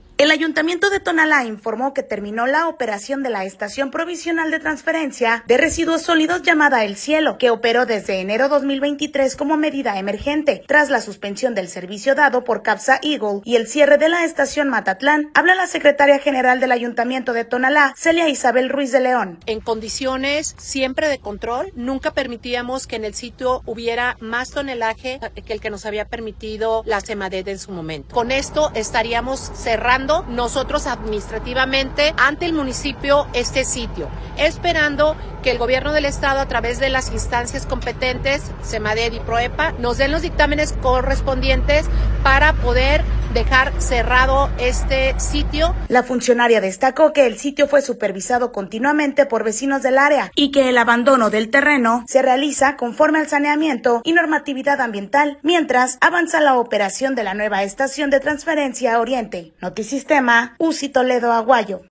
Habla la Secretaria General del Ayuntamiento de Tonalá, Celia Isabel Ruiz de León.